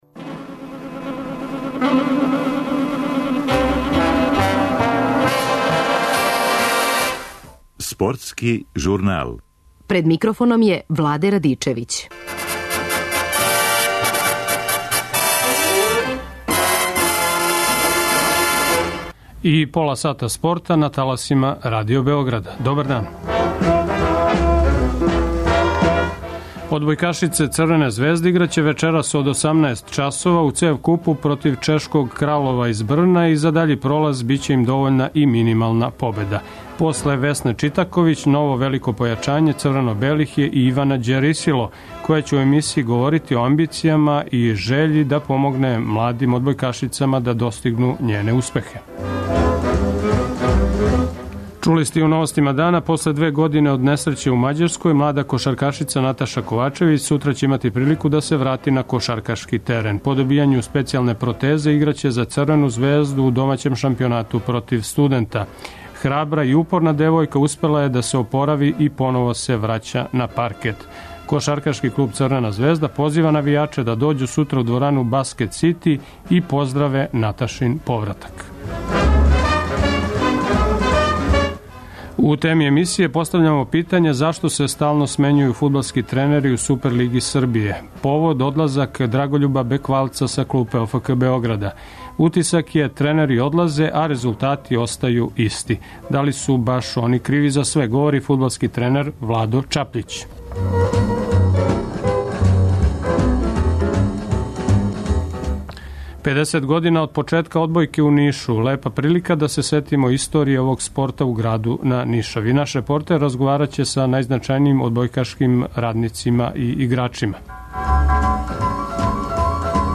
50 година од почетака одбојке у Нишу, лепа прилика да се сетимо историје овог спорта у граду на Нишави. Наш репортер разговараће са најзначајнијим одбојкашким радницима и играчима.